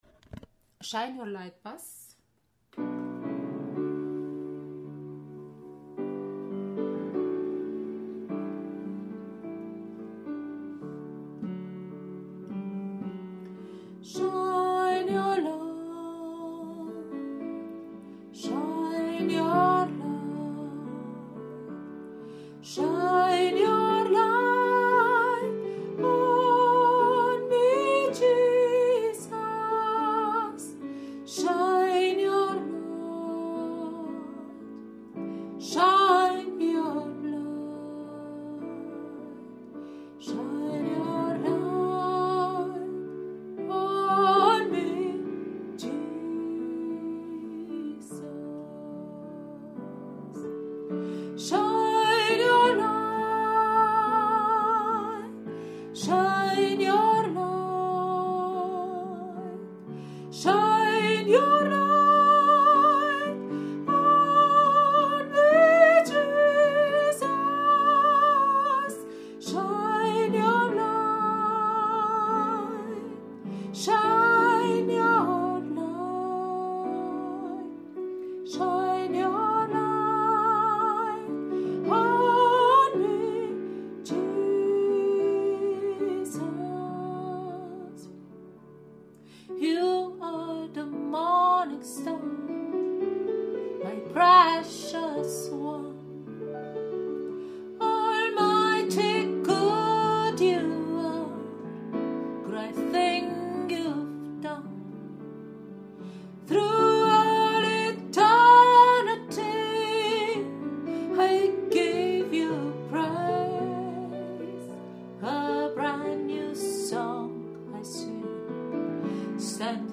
Shine your light Bass